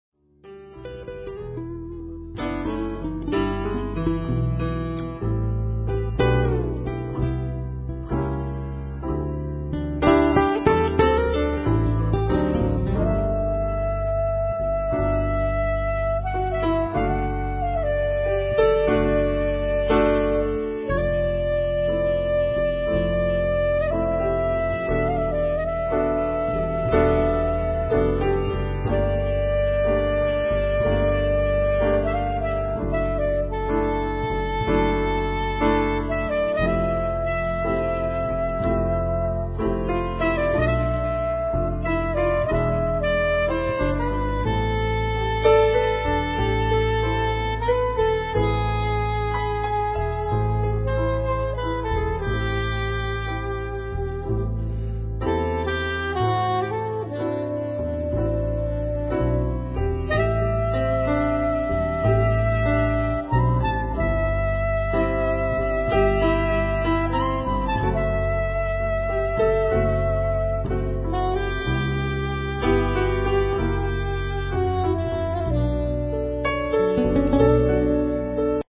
instrumental
Digital CD mastering
Acoustic Guitars
Soprano- and Altsax
Doublebass
Grand Piano
Cello
Percussion.